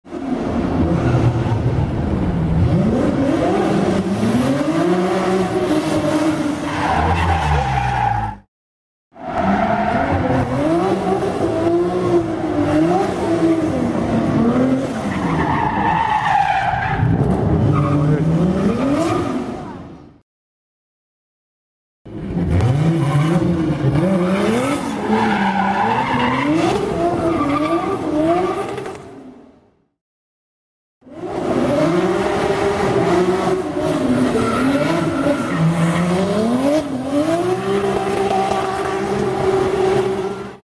Здесь собраны реалистичные записи скольжения автомобилей по разным поверхностям: от асфальтовых треков до сельских грунтовок.
Звук дрифта на парковке между домами — второй вариант